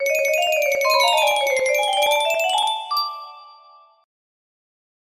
falling down the stairs music box melody